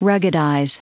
Prononciation du mot ruggedize en anglais (fichier audio)
Prononciation du mot : ruggedize
ruggedize.wav